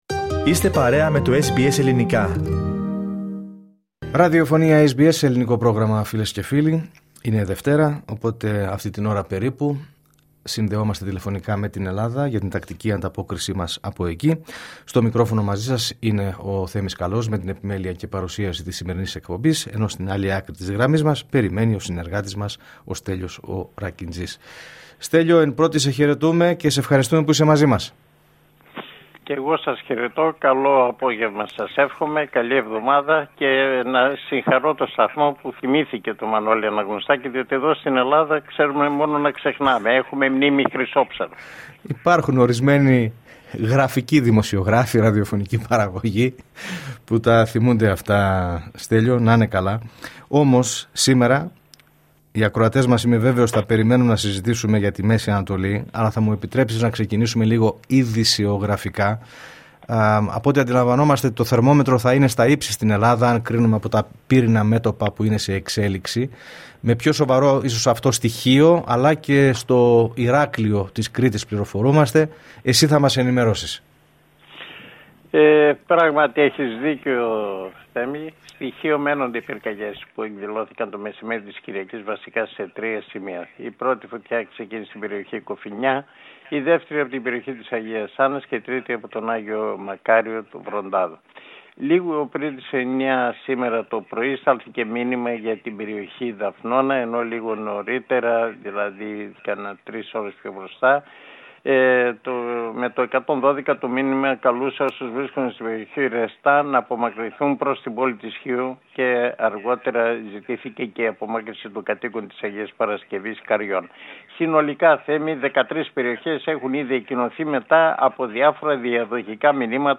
Η εβδομαδιαία ανταπόκριση από Ελλάδα